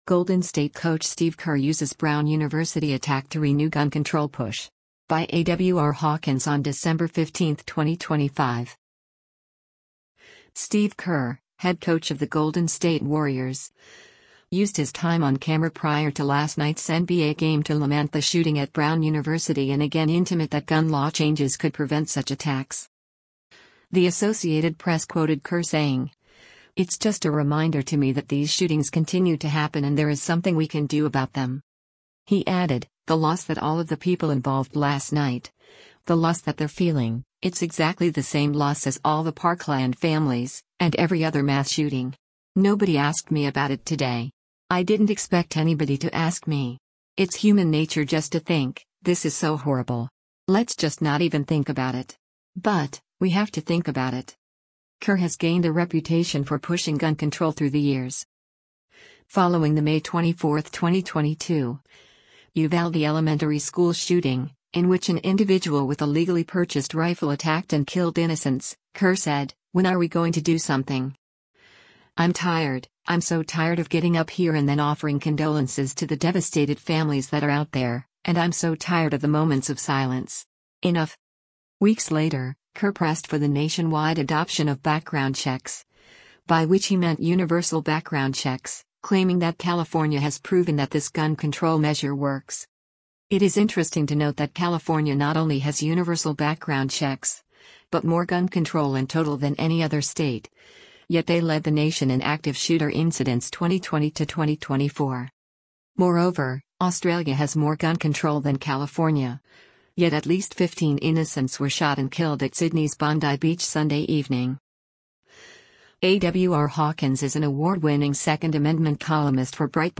Steve Kerr, Head Coach of the Golden State Warriors, used his time on camera prior to last night’s NBA game to lament the shooting at Brown University and again intimate that gun law changes could prevent such attacks.